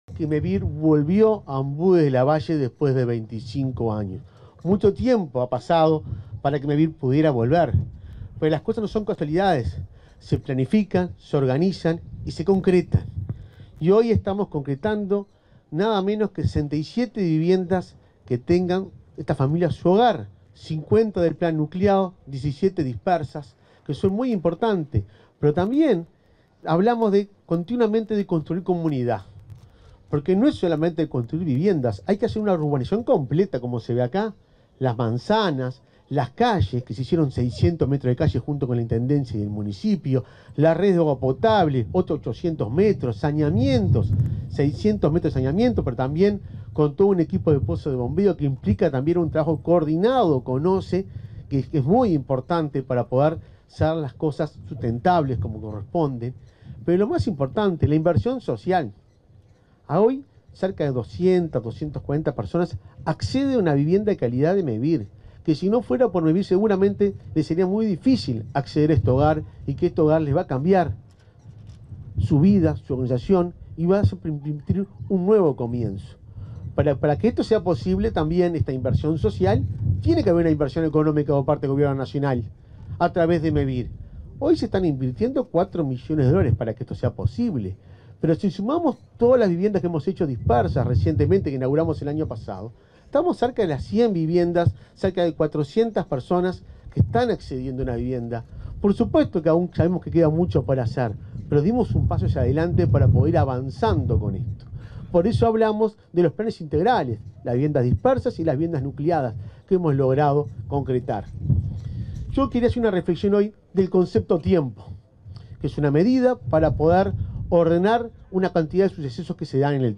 Palabras del presidente de Mevir, Delgado, y el subsecretario de Vivienda, Hackenbruch
Palabras del presidente de Mevir, Delgado, y el subsecretario de Vivienda, Hackenbruch 02/10/2024 Compartir Facebook X Copiar enlace WhatsApp LinkedIn En la ceremonia de inauguración de 67 viviendas nucleadas en Ombúes de Lavalle, este 2 de octubre, se expresaron el presidente de Mevir, Juan Pablo Delgado, y el subsecretario de Vivienda y Ordenamiento Territorial, Tabaré Hackenbruch.